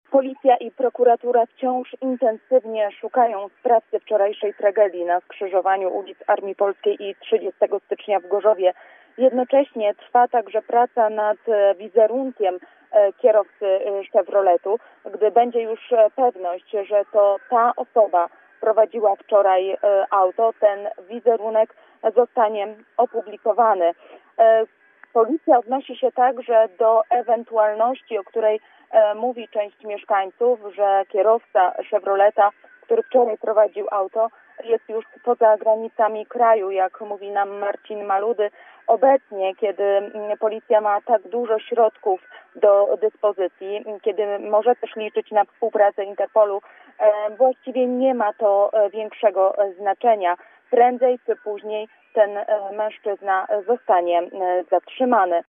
pytała dzisiaj w Komendzie Wojewódzkiej Policji, na jakim etapie są działania w tej sprawie.